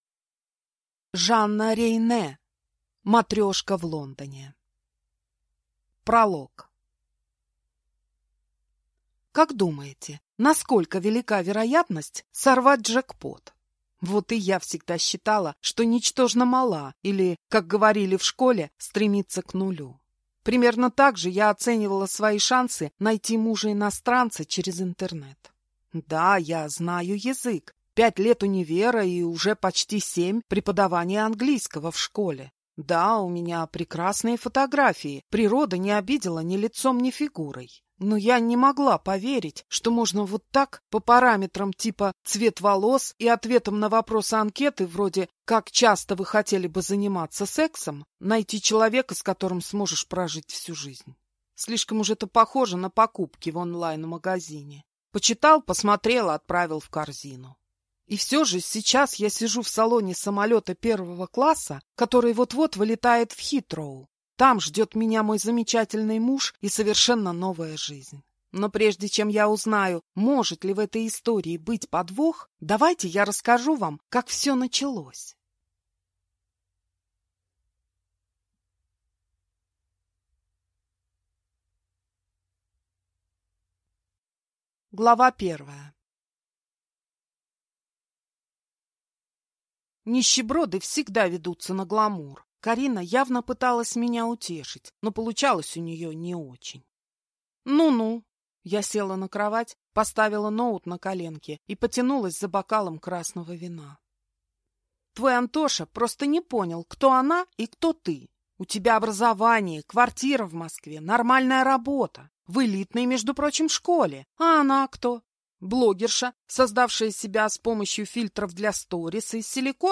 Аудиокнига Матрешка в Лондоне | Библиотека аудиокниг
Прослушать и бесплатно скачать фрагмент аудиокниги